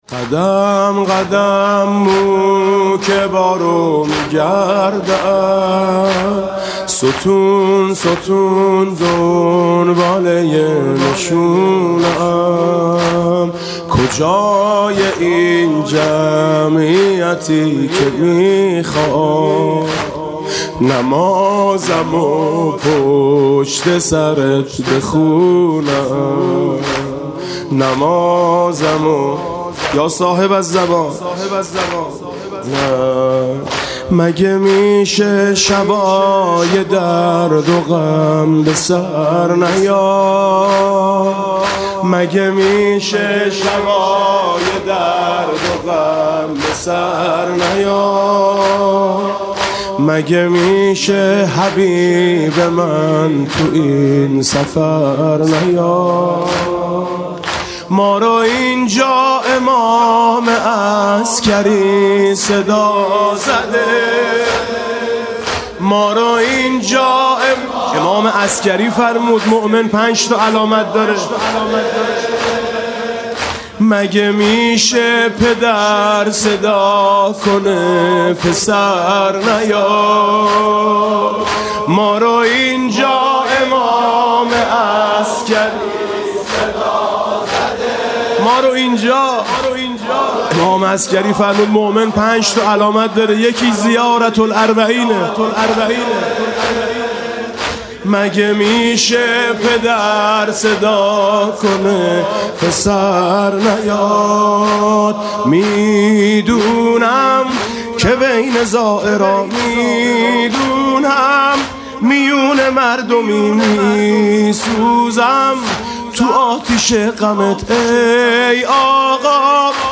مسیر پیاده روی نجف تا کربلا [عمود ۹۰۹]
مناسبت: ایام پیاده روی اربعین حسینی
با نوای: حاج میثم مطیعی
بر شام بی‌ستاره‌ی تو آسمان گریست (روضه)